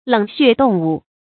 冷血動物 注音： ㄌㄥˇ ㄒㄩㄝˋ ㄉㄨㄙˋ ㄨˋ 讀音讀法： 意思解釋： 體溫隨環境溫度的改變而變化的動物。